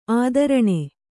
♪ ādaraṇe